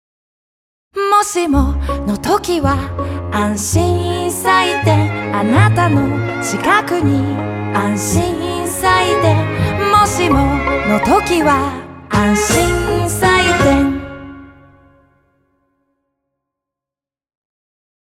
TVCM
ACOUSTIC / CLASSIC